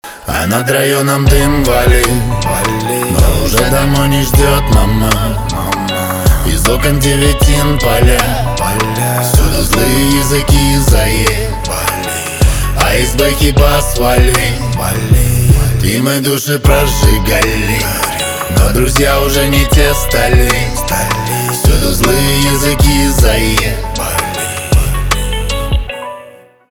русский рэп , грустные , печальные , битовые , басы
гитара